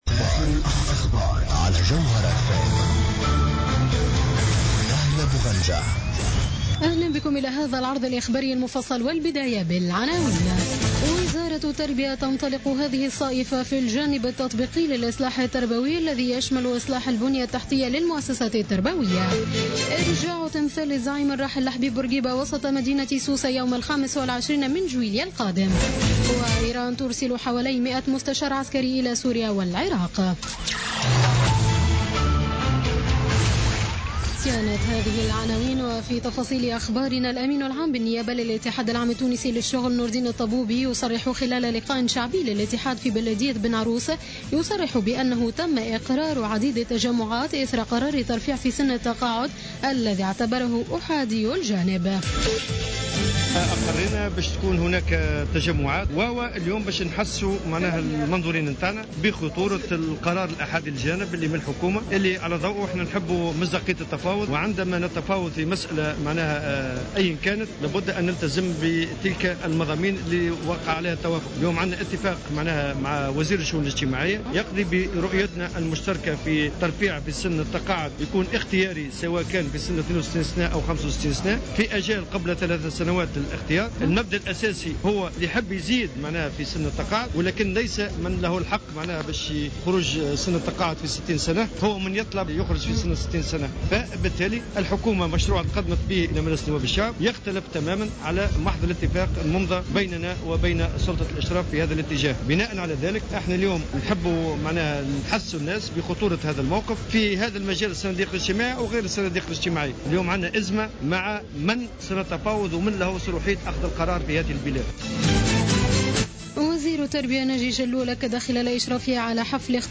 نشرة أخبار السابعة مساء ليوم السبت 14 ماي 2016